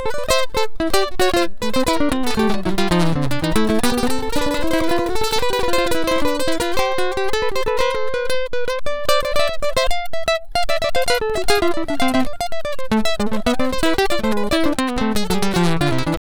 Ala Brzl 2 Gts Dry-A.wav